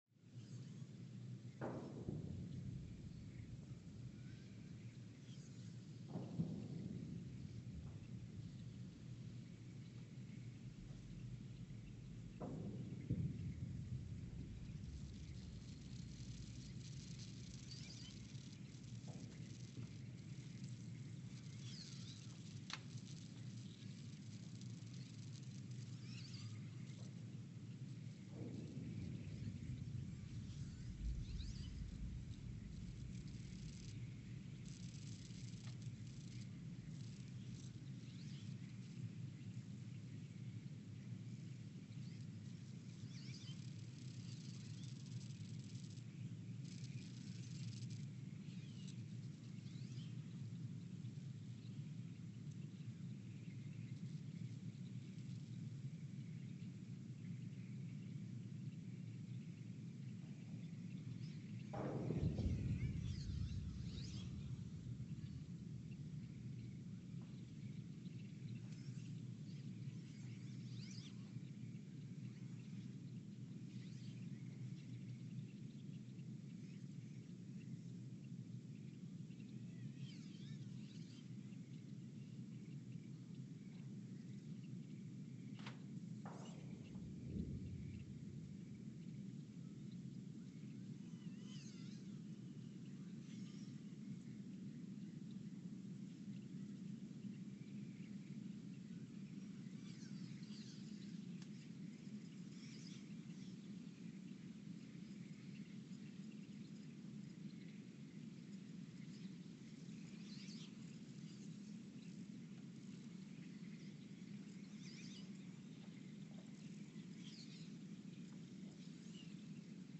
The Earthsound Project: Ulaanbaatar, Mongolia (seismic) archived on September 12, 2020
The Earthsound Project is an ongoing audio and conceptual experiment to bring the deep seismic and atmospheric sounds of the planet into conscious awareness.
Sensor : STS-1V/VBB
Speedup : ×900 (transposed up about 10 octaves)
Loop duration (audio) : 11:12 (stereo)